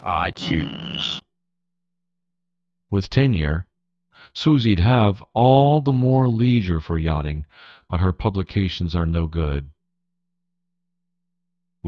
text-to-speech voice-cloning